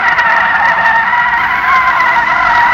skid loop 4.aiff